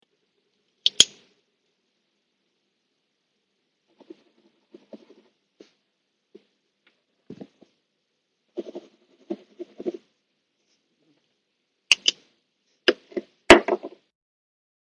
描述：咖啡机生产热水泡茶的记录。短暂的闪动表示这个过程已经结束。使用奥林巴斯VN480录音机录制。
Tag: 咖啡机 办公